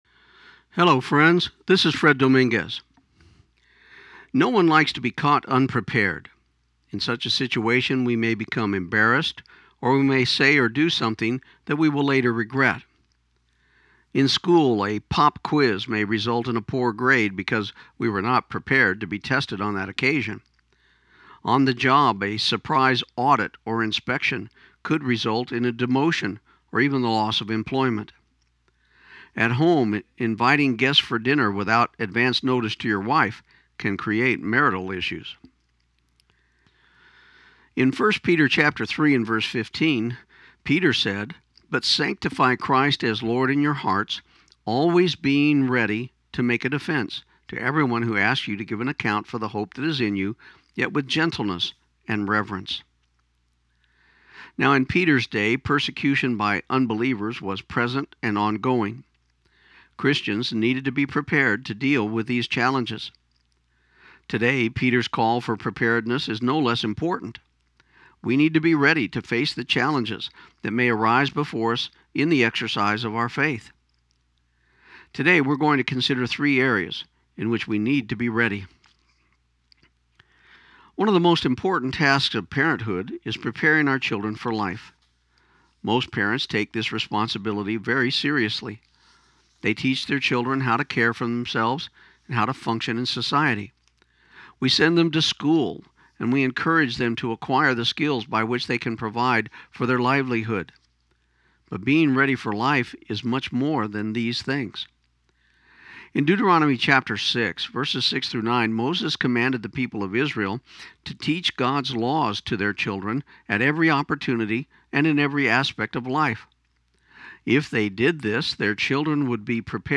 This program aired on KIUN 1400 AM in Pecos, TX on November 11, 2016.